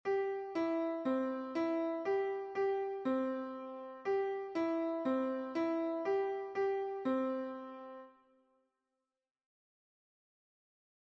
Pero antes, a ver que tal vas de oído, relacionando os seguintes audios coas partituras que se che ofrecen, un ditado melódico cun propósito real.